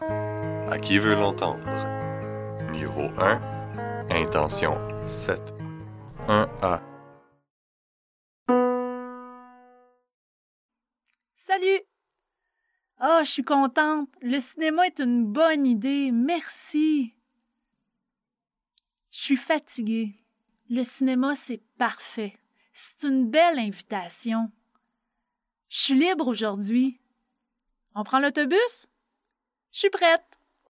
Indicatif présent : Associer [ʃy] et [ʃɥi] à je suis o